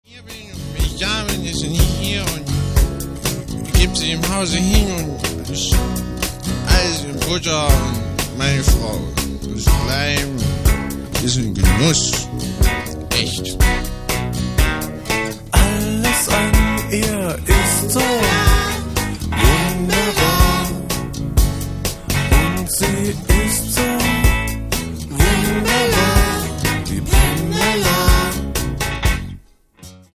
Disc 1 floors it with brutal punk shorts.